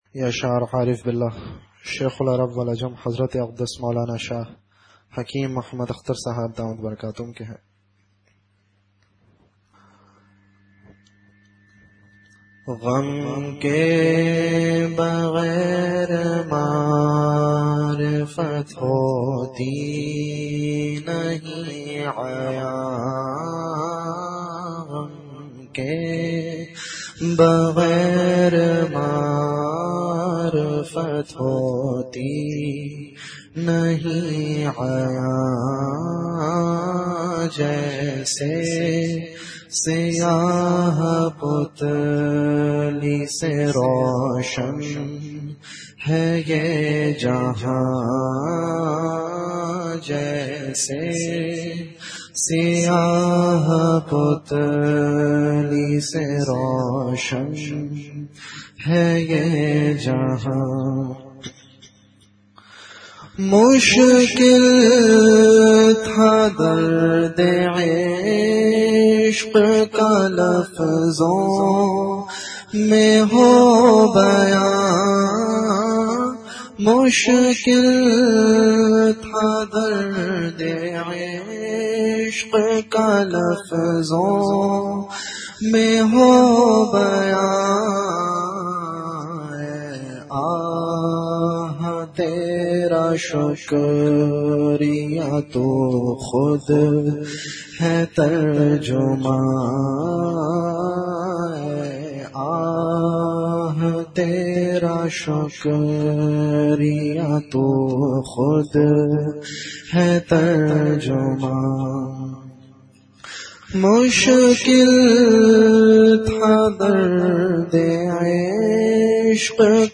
Delivered at Jamia Masjid Bait-ul-Mukkaram, Karachi.
Ashaar · Jamia Masjid Bait-ul-Mukkaram, Karachi